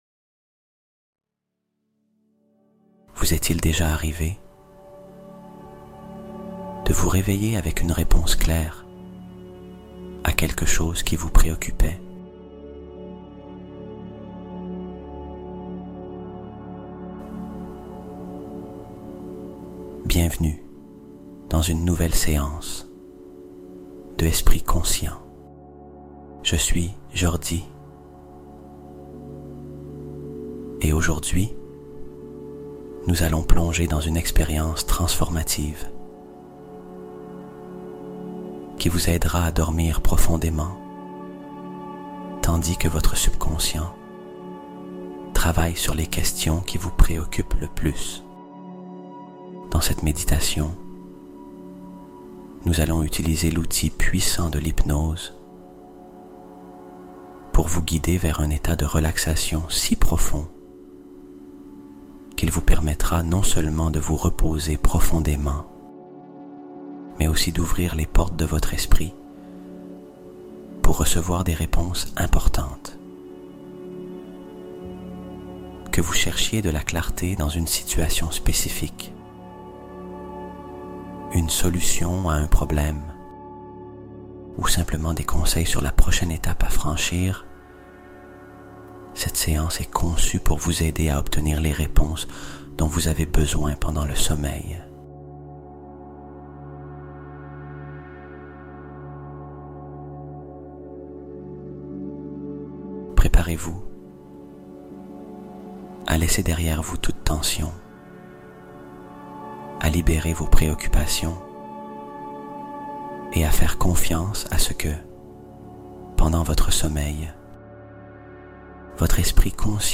Reçois Les Réponses Pendant Ton Sommeil : Hypnose Spéciale Pour Résoudre Tes Problèmes en Dormant